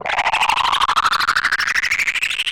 RI_ArpegiFex_95-02.wav